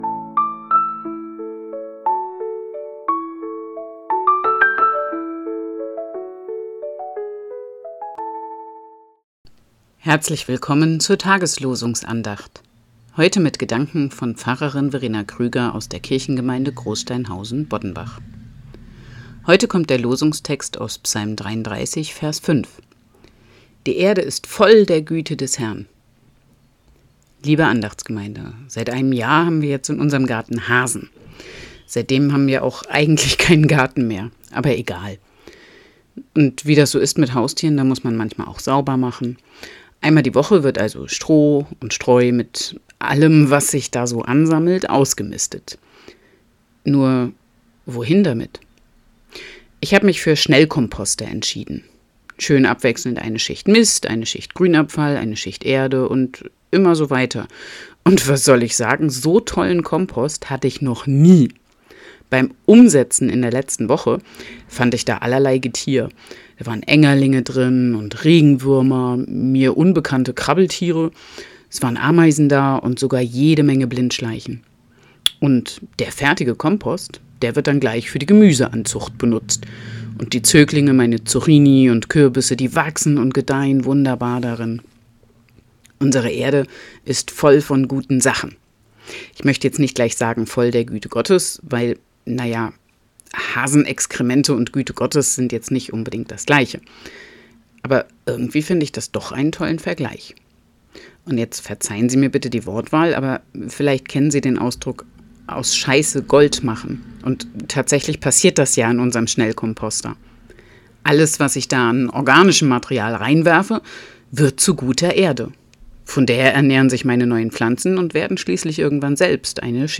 Losungsandacht für Montag, 14.04.2025